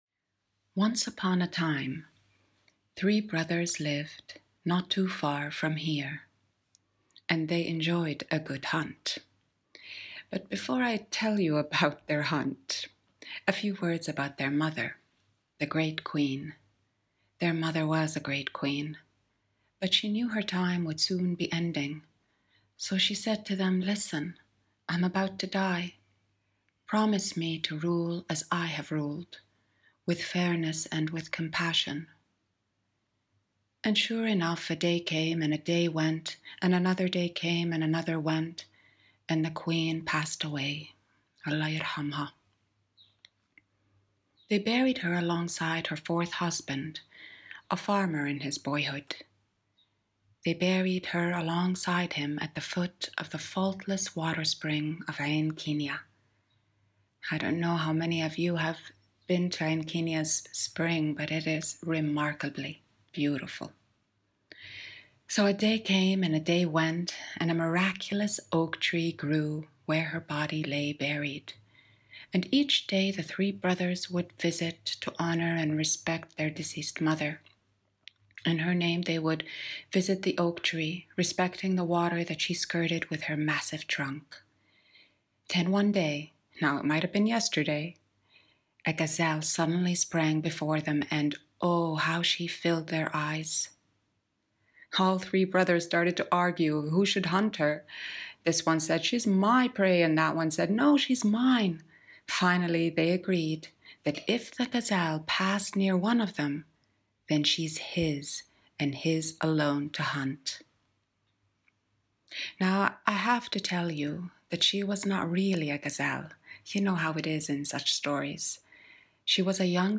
Listen to the artist tell the folktale A brother, a gazelle and moving the mountain to let the sun shine through.